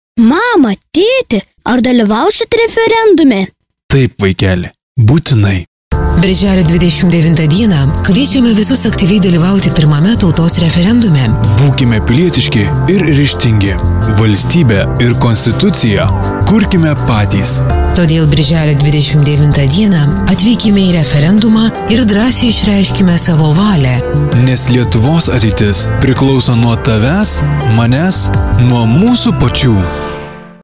Garsinis įrašas tinkantis RADIJO eterio sklaidai:
Informacinis pranesimas radijo stotyse